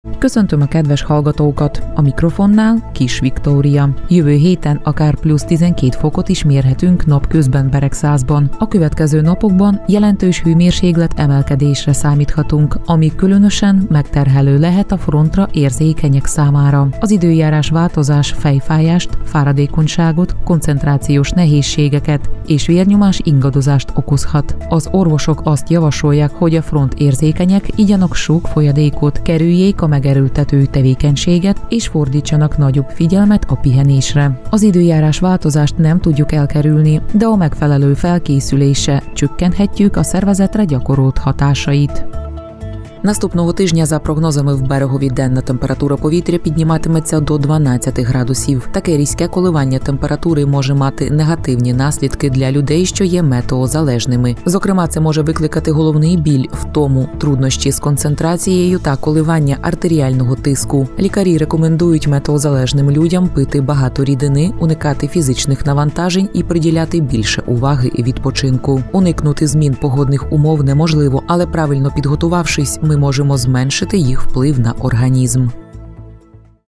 Magyar és ukrán nyelven olvas híreket